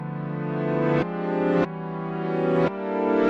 描述：摇摆不定的低音循环
Tag: 145 bpm Dubstep Loops Bass Wobble Loops 1.11 MB wav Key : Unknown Mixcraft